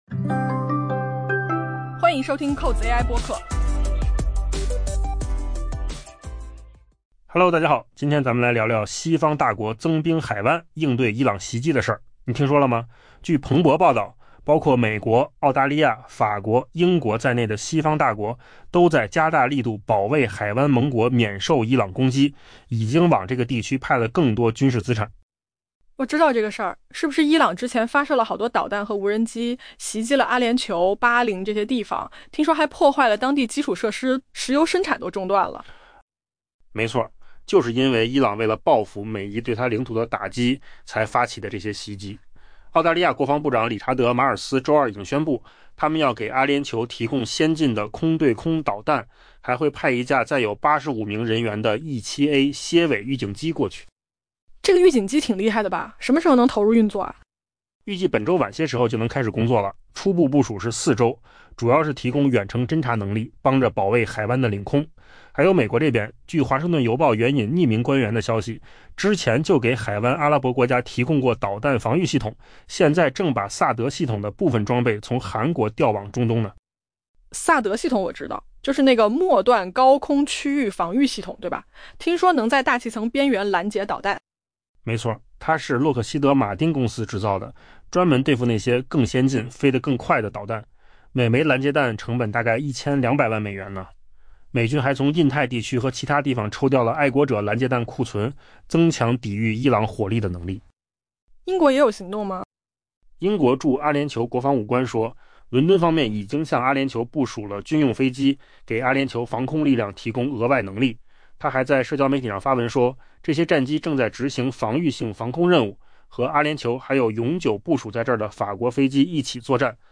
AI播客：换个方式听新闻 下载mp3
音频由扣子空间生成
西方大国增兵海湾 应对伊朗袭击.mp3